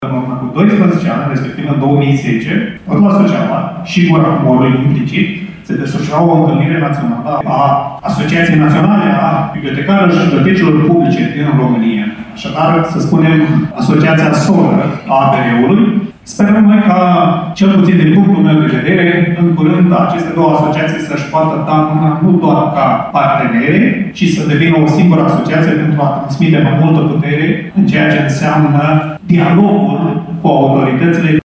În cuvântul său de salut